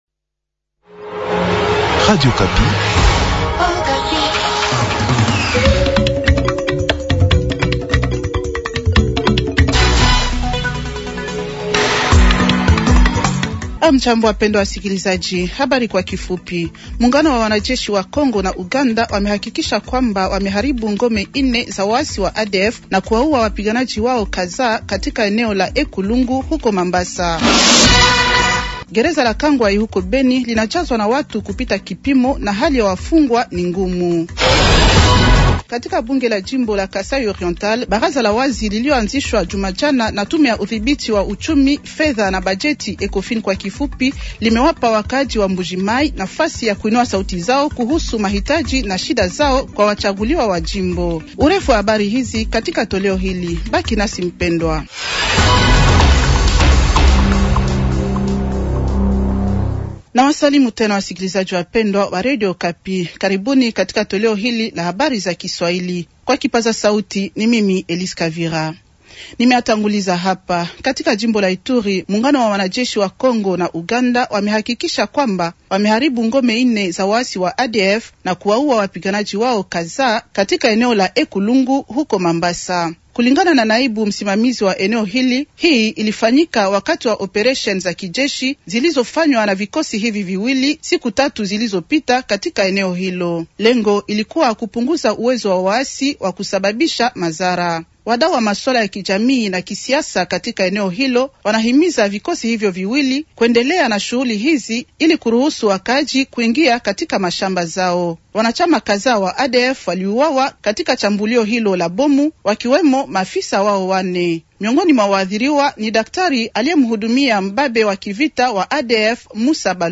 Journal Swahili de mardi matin 100326